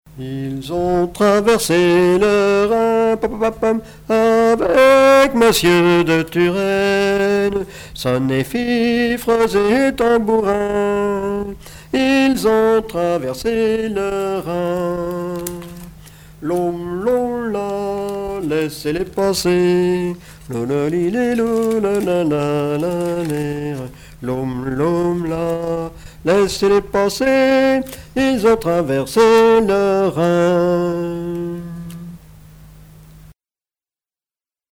Mémoires et Patrimoines vivants - RaddO est une base de données d'archives iconographiques et sonores.
Genre laisse
Pièce musicale inédite